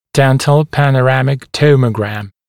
[‘dentl ˌpænə’ræmɪk ˈtəumə(u)græm] [ˈtɔm-] [‘дэнтл ˌпэнэ’рэмик ˈтоумо(у)грэм] [ˈтом-] панорамная томограмма зубных рядов